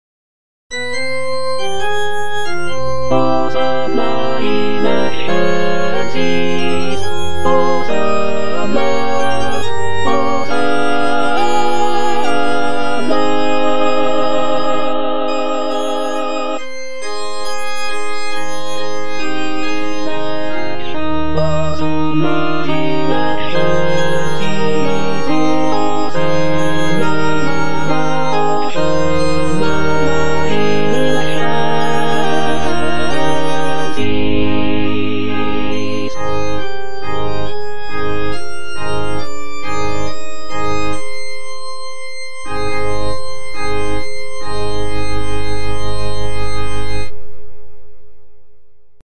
J.G. RHEINBERGER - MASS IN C OP. 169 Benedictus (choral excerpt only) (All voices) Ads stop: auto-stop